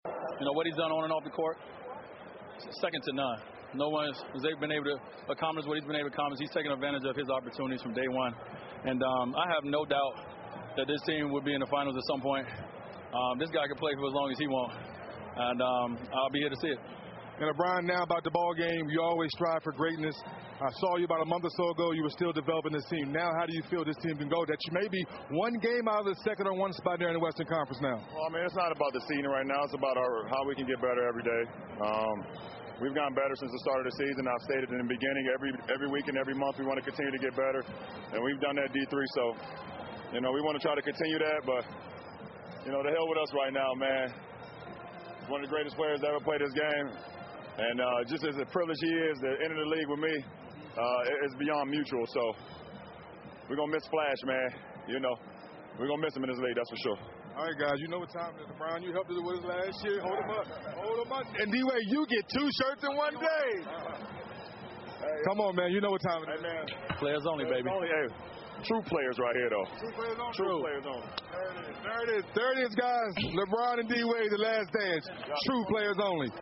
篮球英文堂 第250期:詹韦最后一战采访(2) 听力文件下载—在线英语听力室